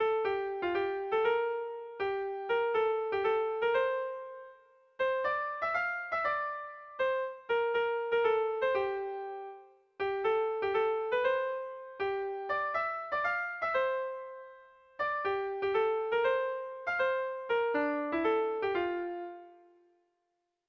Kontakizunezkoa
Zortziko txikia (hg) / Lau puntuko txikia (ip)
ABDE